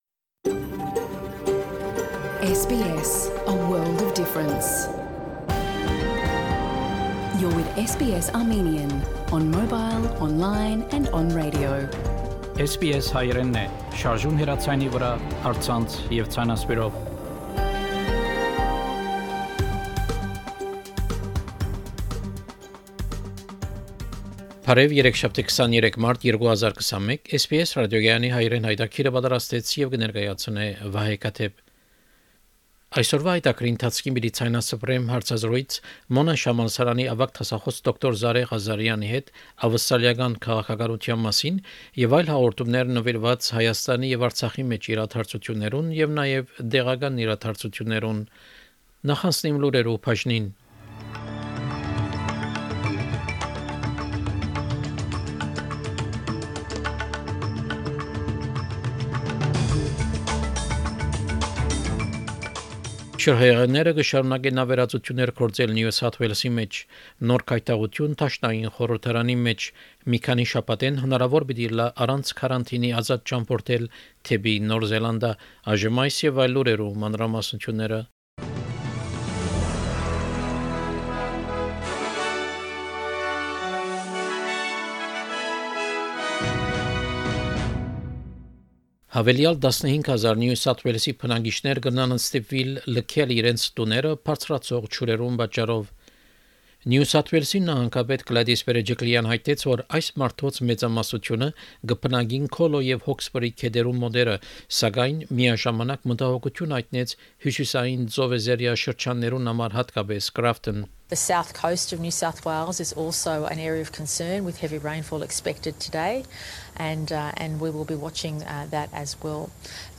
SBS Armenian news bulletin – 23 March 2021
SBS Armenian news bulletin from 23 March 2021 program.